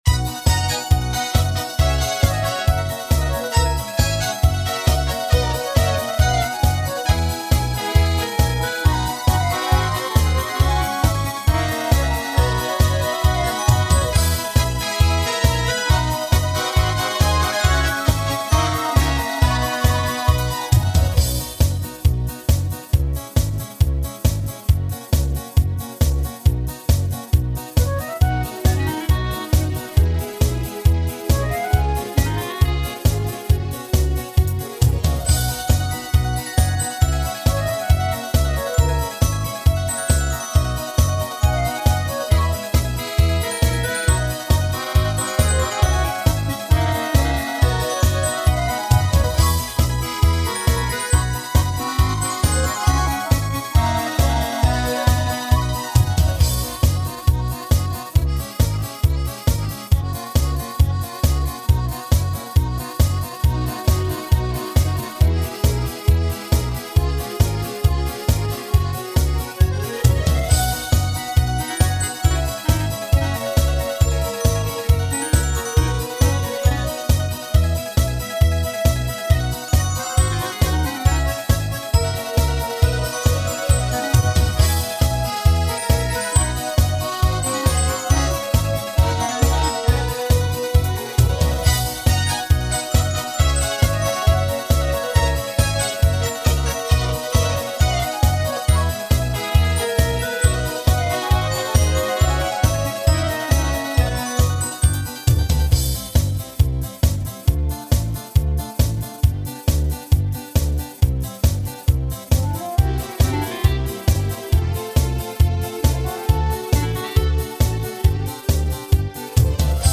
Главная » Песни » Песни о школе
Скачать минус